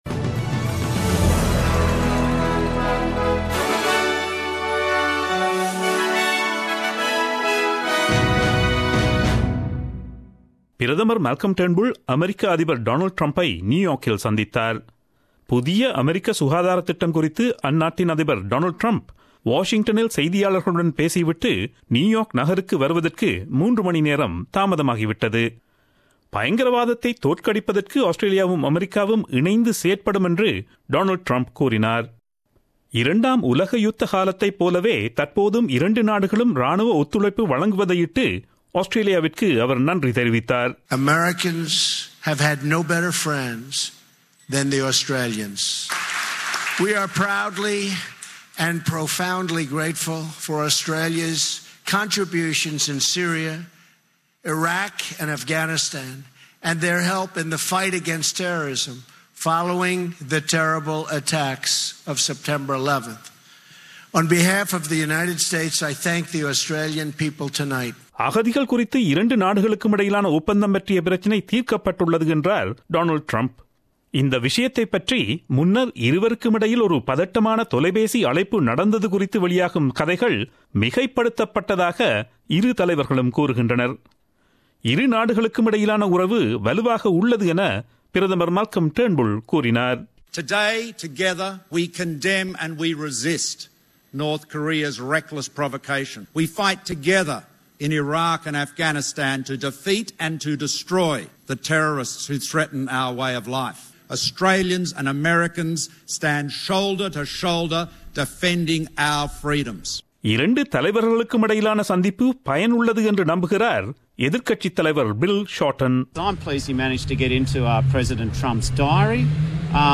Australian news bulletin aired on Friday 05 May 2017 at 8pm.